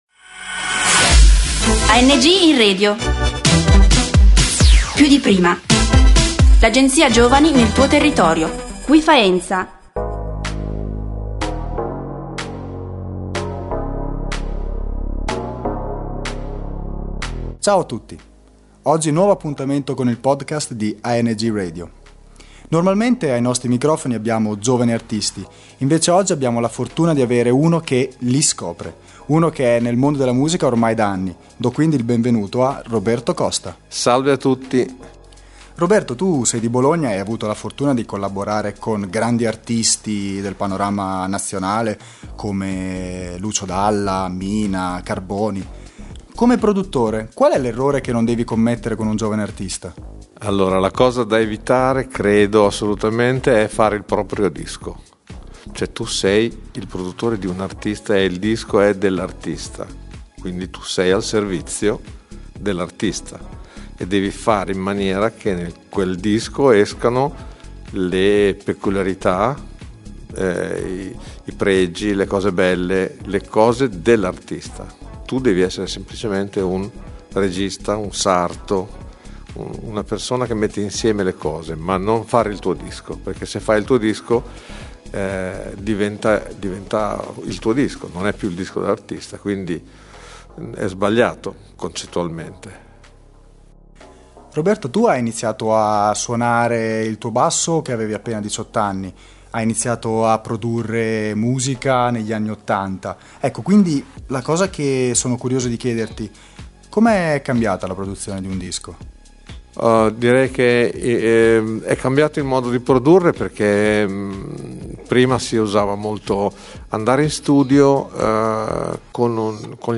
AngInRadio Faenza indie: intervista